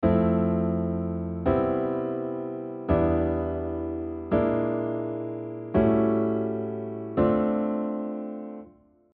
The natural dominant chord of Dm7 is an A7.
But before finally moving to the A7, we also use the French sixth as a predominant chord.
In the example above, the D minor chord is approached by a 2 – 5 progression to make the D minor chord feel like ‘home’